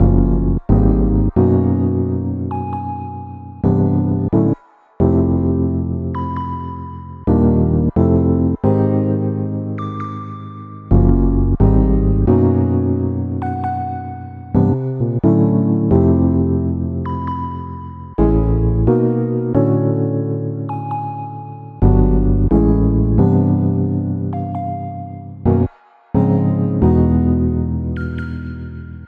描述：用FenderRhodes钢琴演奏的温暖的爵士和弦。
Tag: 66 bpm Jazz Loops Piano Loops 4.90 MB wav Key : Unknown